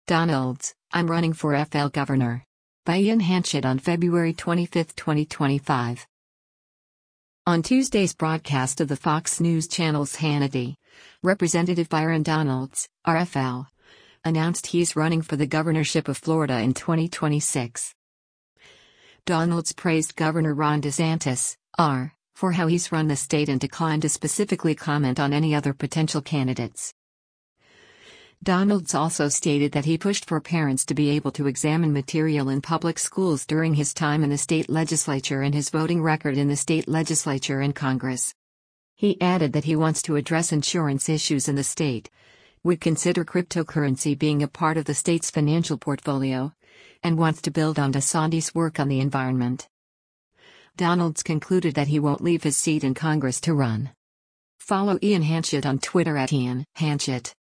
On Tuesday’s broadcast of the Fox News Channel’s “Hannity,” Rep. Byron Donalds (R-FL) announced he’s running for the governorship of Florida in 2026.